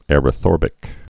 (ĕrə-thôrbĭk)